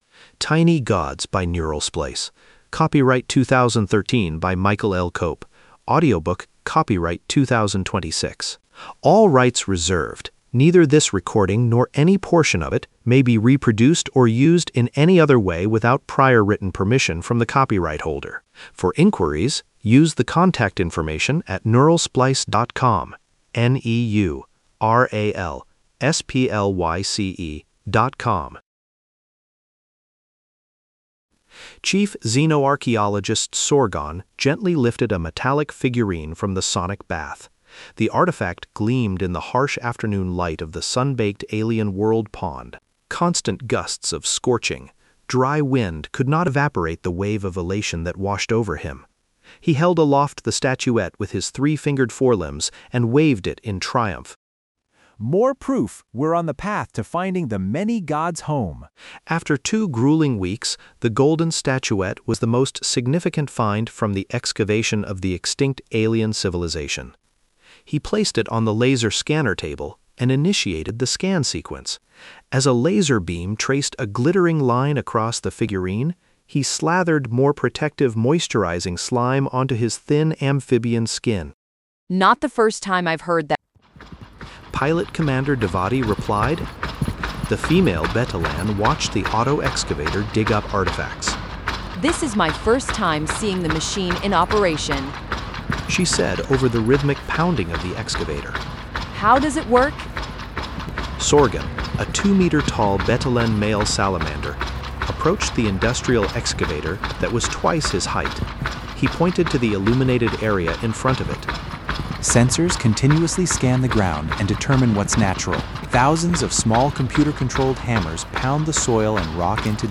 When he discovers a treasure trove of idols on a long dead planet, it seems his theory of a universal 'True Religion' may be factual. Return to Bookshelf Tiny Gods Donate up to $3 Download ebook Listen to Audiobook Download audiobook